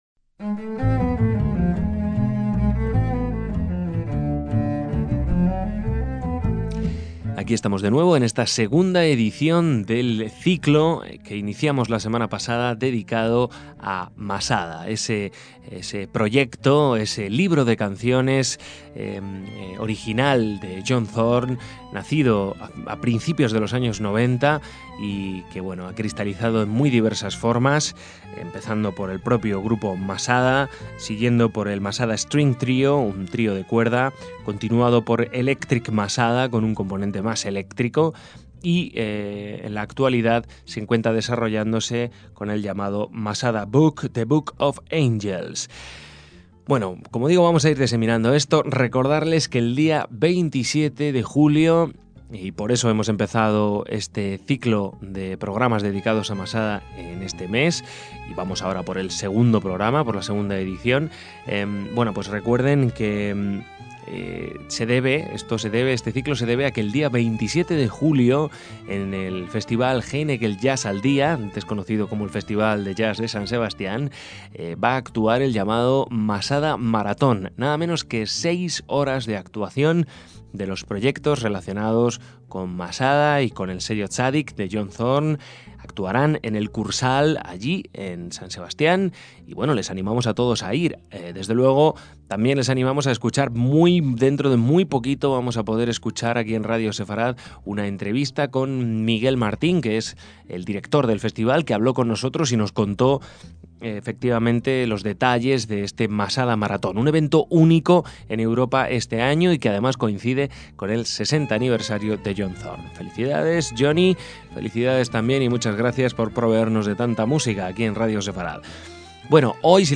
en formación de trío de cuerdas